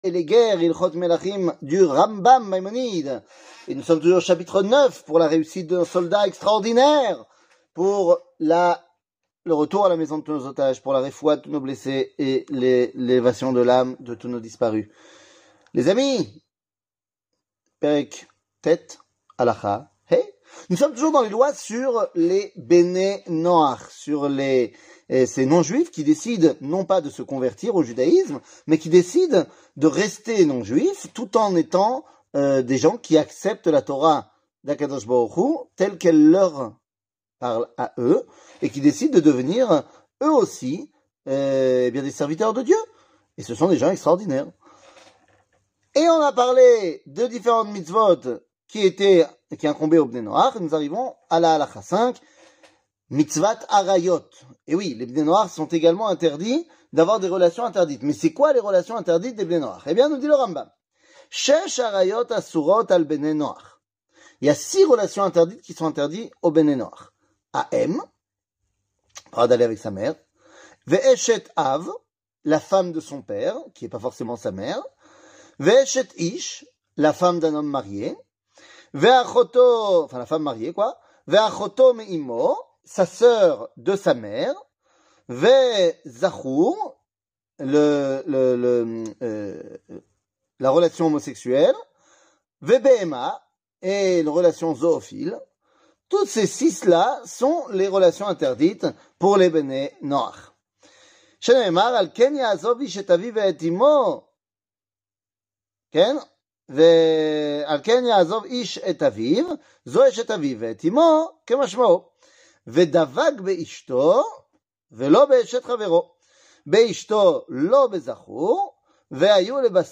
שיעור מ 04 ינואר 2024